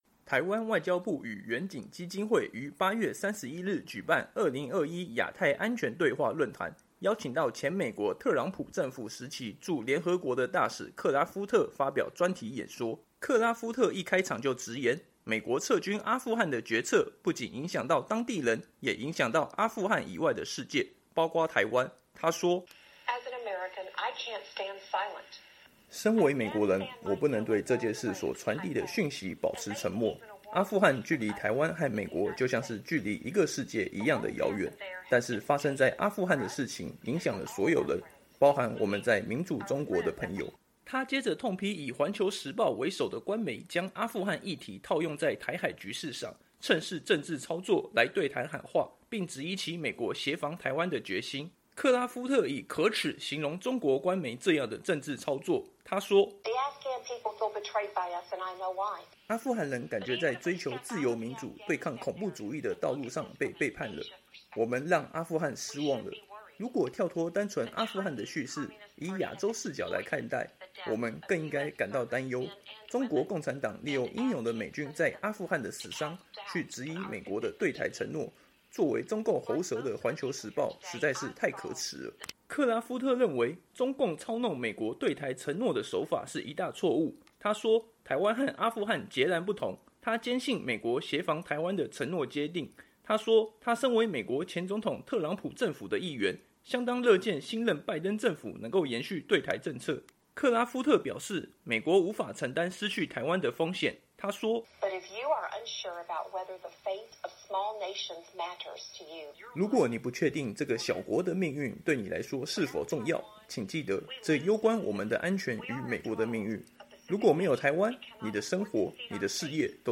台湾外交部与远景基金会于8月31日举办“2021亚太安全对话” 论坛，邀请到前美国特朗普政府时期驻联合国的大使克拉夫特(Kelly Craft)发表专题演说。